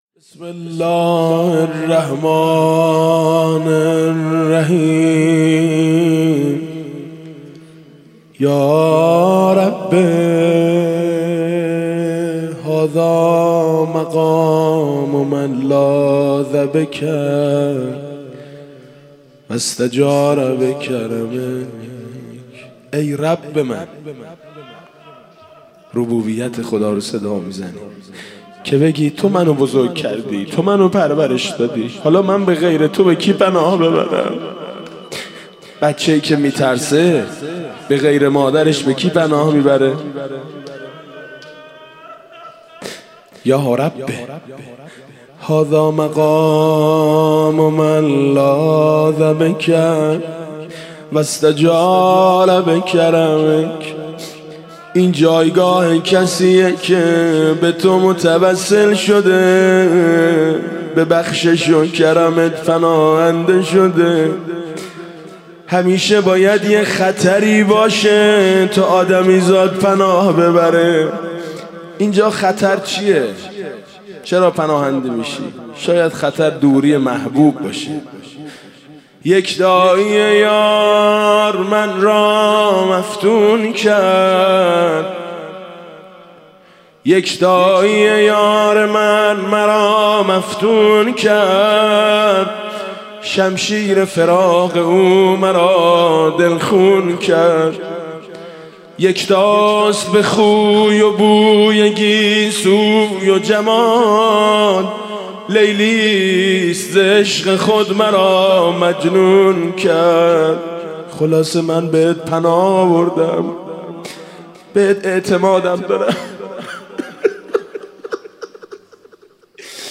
شب نهم رمضان 96 - هیئت شهدای گمنام - فرازهایی از دعای ابوحمزه ثمالی